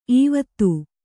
♪ īvattu